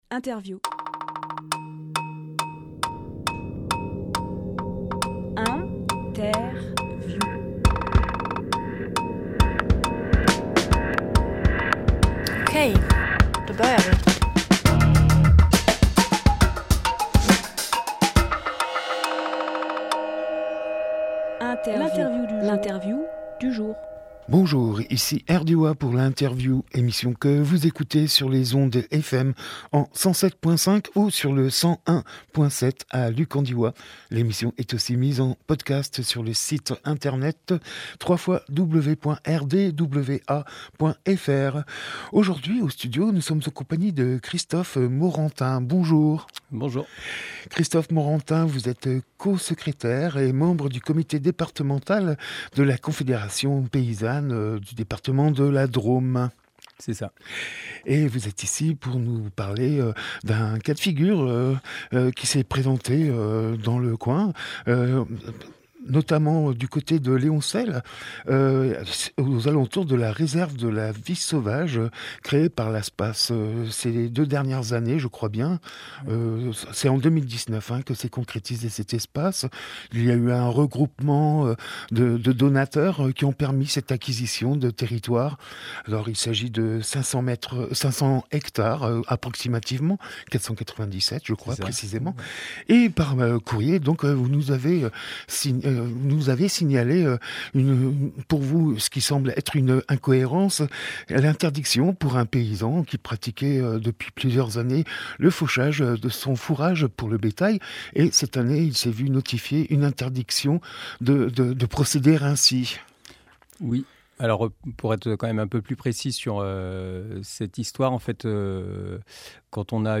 Emission - Interview La Confédération Paysanne contre l’exclusion des paysans de leurs terres Publié le 16 décembre 2022 Partager sur…
16.12.22 Lieu : Studio RDWA Durée